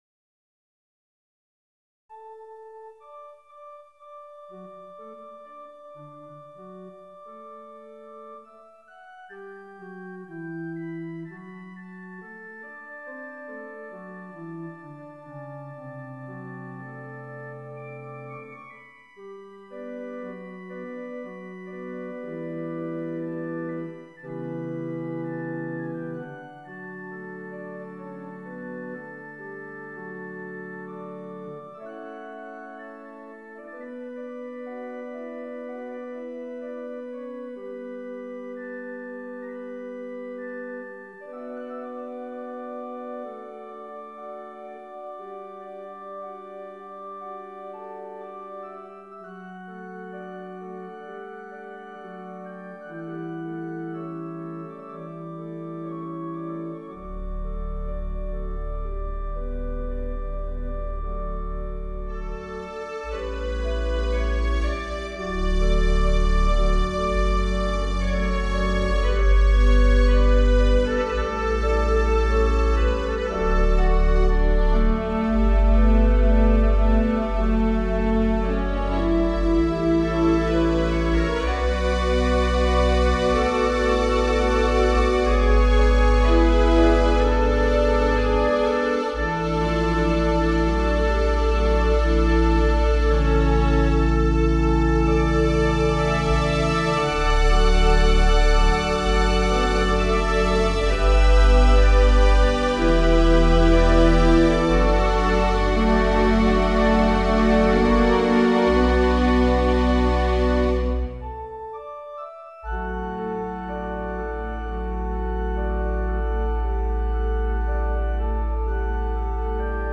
Virtual Orchestral Organ
4/54 Symphonic VI Virtual Orchestral Organ.